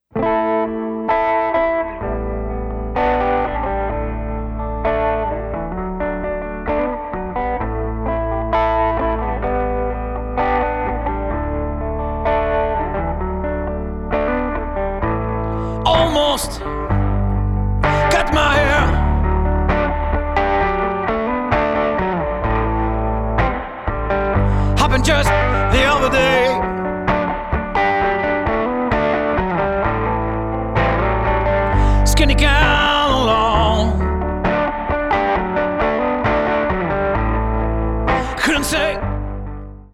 Vocals, Guitars
Drums